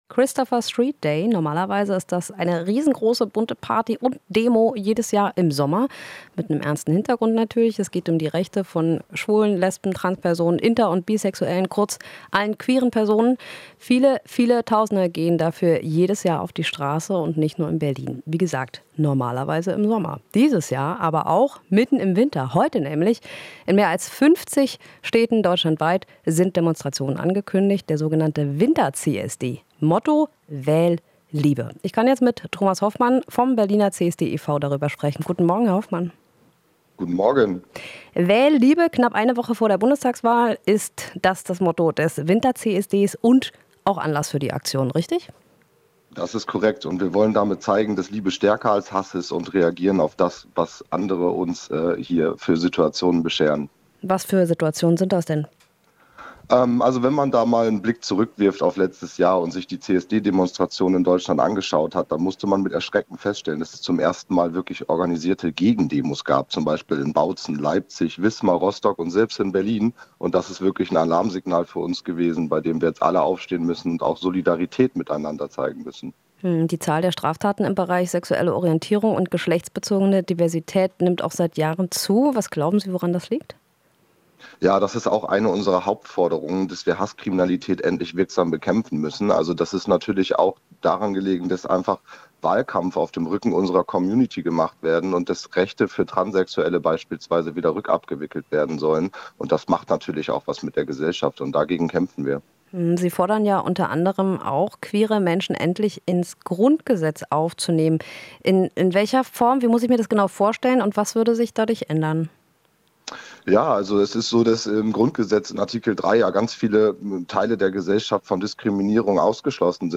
Interview - Vor der Wahl: Erster Winter-CSD in mehr als 50 Städten